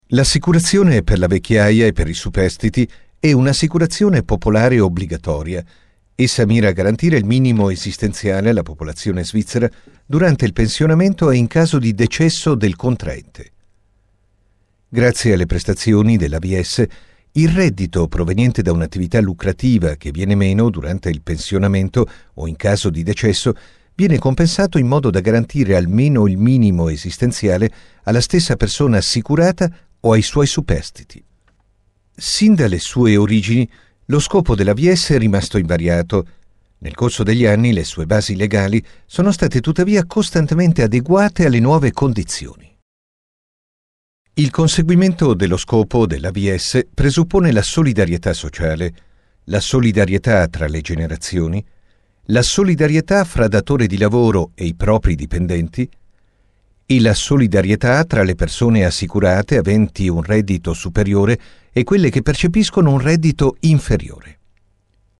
italienischer Sprecher
Sprechprobe: Industrie (Muttersprache):
voice over talent italien